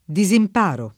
disimparo [ di @ imp # ro ]